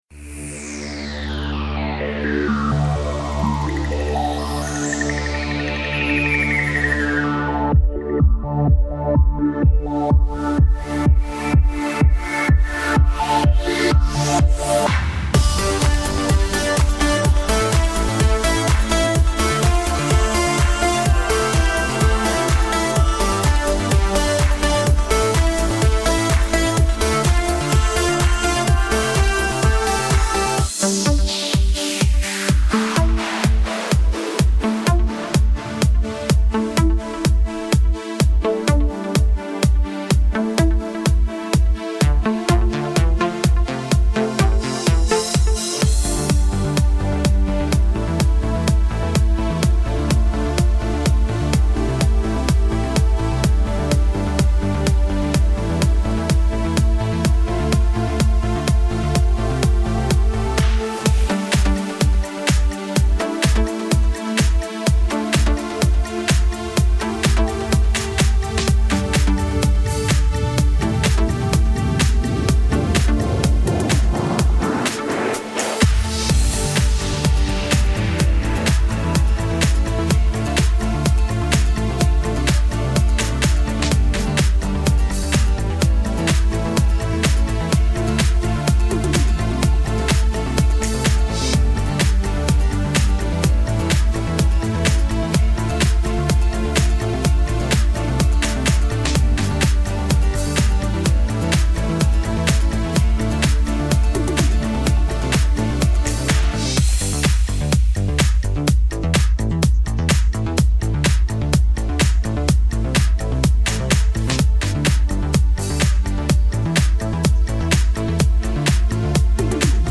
караоке
минусовка